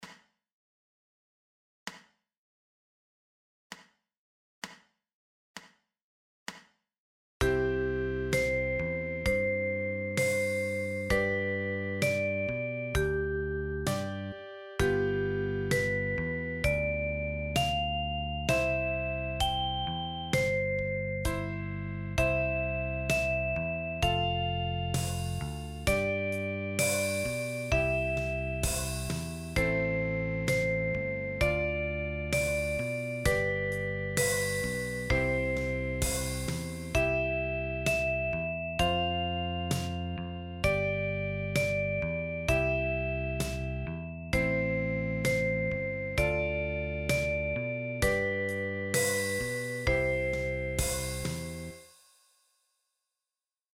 eBook 46 Kinderlieder für die Okarina – mit Sounds